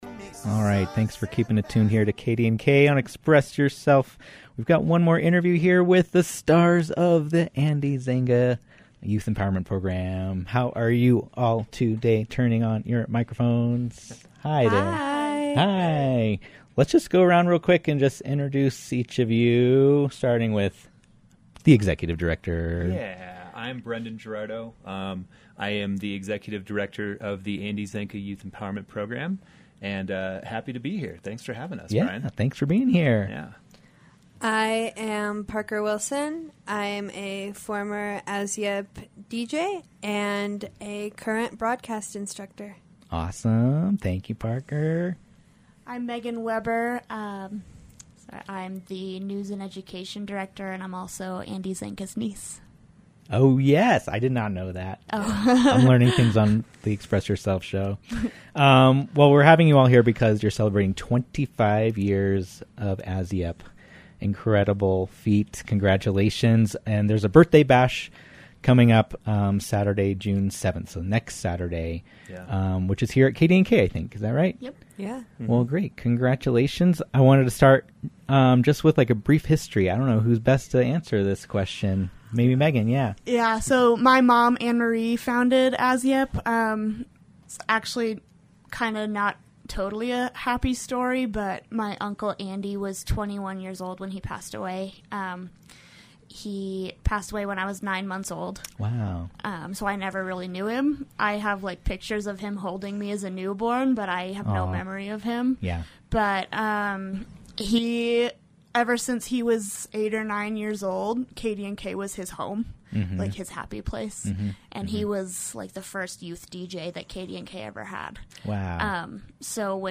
The Andy Zanca Youth Empowerment Program hosted the fourth part of a series focusing on teen mental health in the Roaring Fork Valley. The discussion took place on April 22nd and aired in June to celebrate Pride Month.